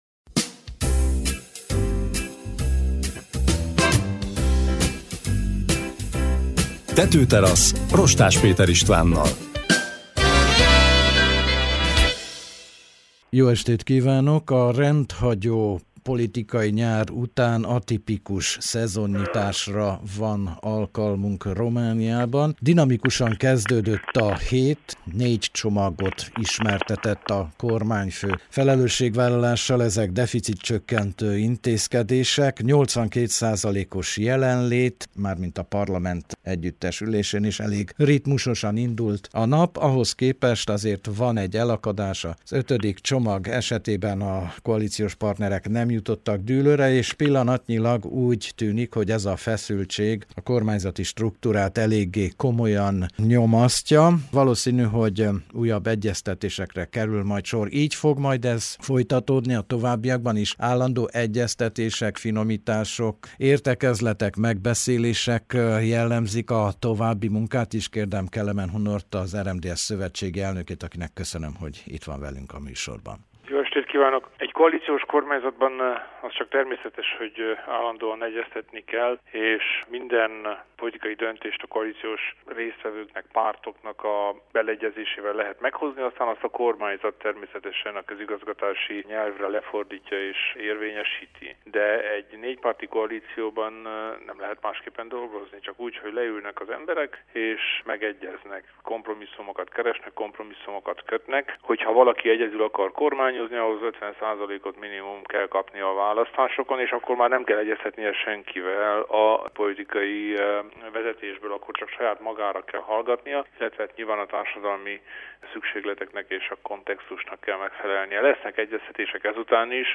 Kelemen Hunor szövetségi elnök a Tetőterasz vendége volt, két egyeztetés, tárgyalás között jelentkezett be Bukarestből a műsorba.